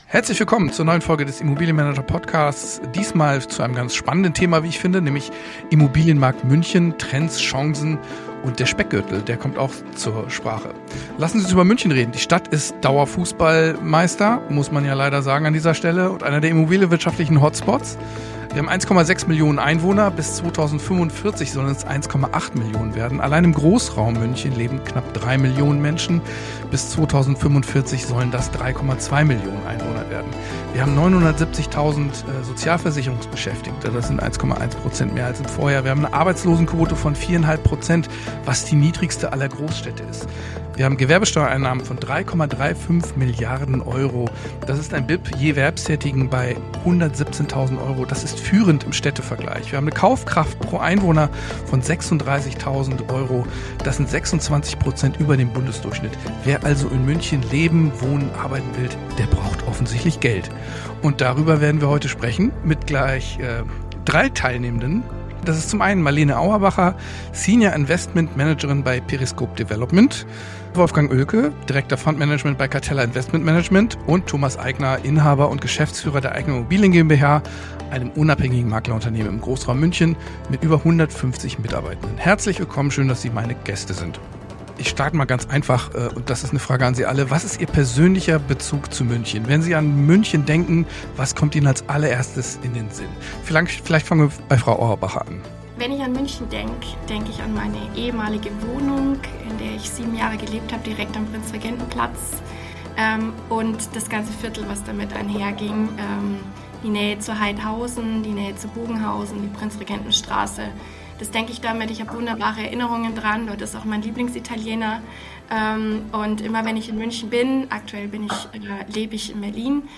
Die Runde spricht über rückläufige Umsätze bei gleichzeitig stabilen Preisen, über neue Chancen im Bestand, ESG als Preistreiber – und darüber, warum der wahre Engpass nicht der Zins, sondern das Baurecht ist.